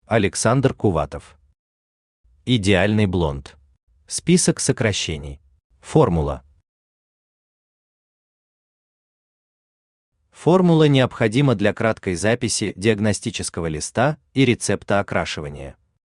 Аудиокнига Идеальный блонд | Библиотека аудиокниг
Aудиокнига Идеальный блонд Автор Александр Сергеевич Кувватов Читает аудиокнигу Авточтец ЛитРес.